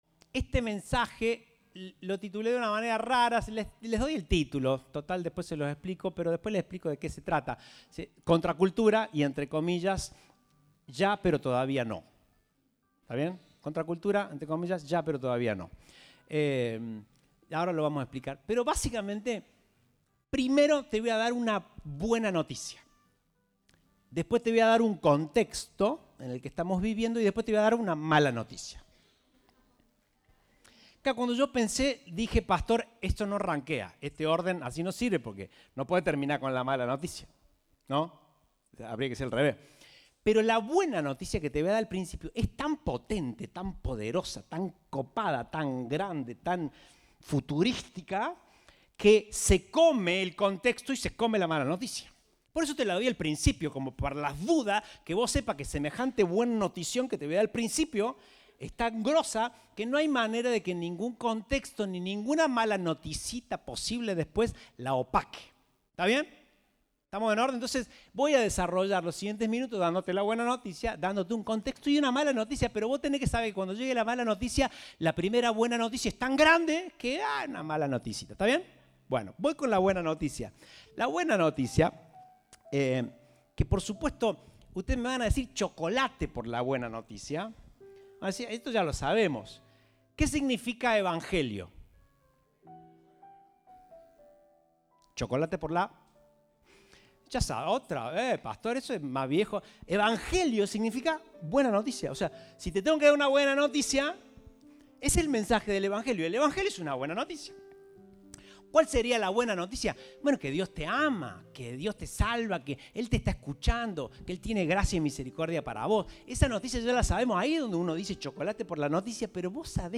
Compartimos el mensaje del Domingo 09 de Julio de 2023